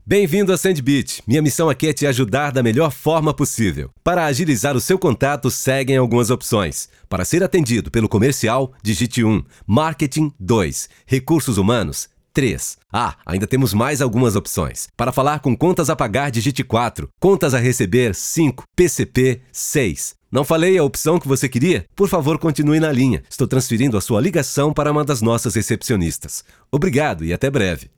offering a confident, versatile baritone voice
IVR
Neumann tlm103, Audio Technica AT 4033, Avalon vt737SP, Audient Id14, Yamaha HS50, Mac Mini M1